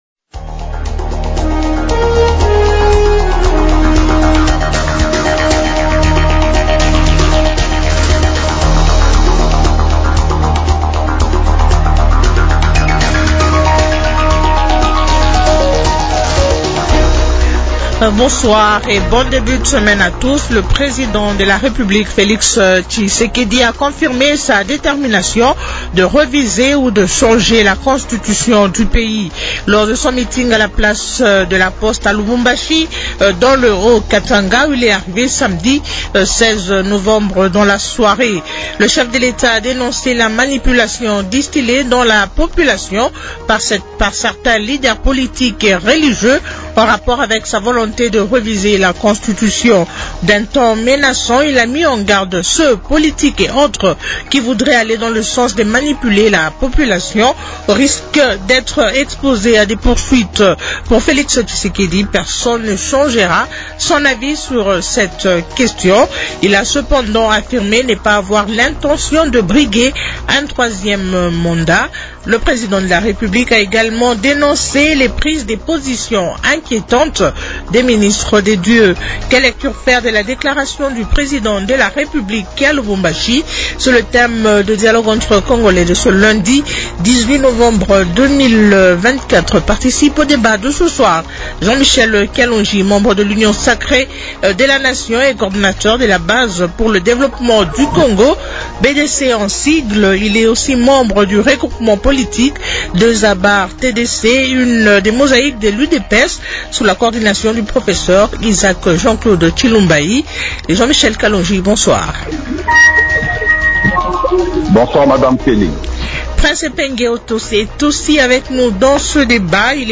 Il l’a affirmé lors de son meeting à la place de la Poste à Lubumbashi dans le Haut-Katanga.